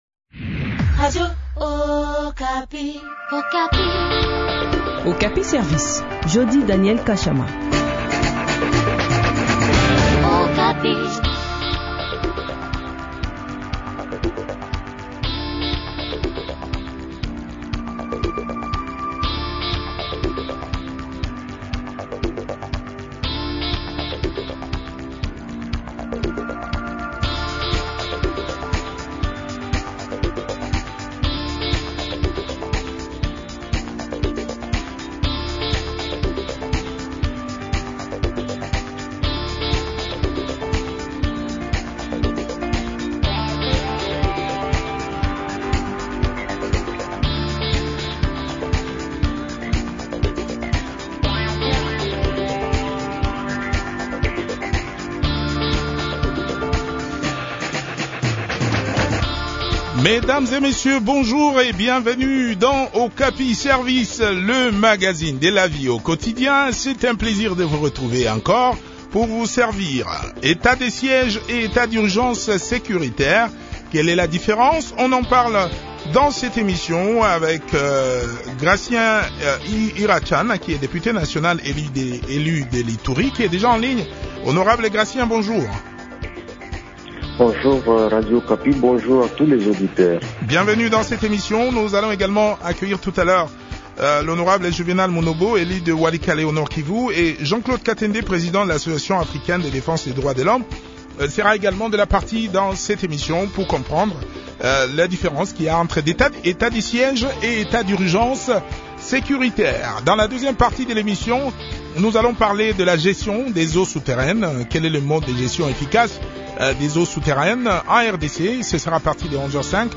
chercheur en droit ont aussi pris part à cette interview.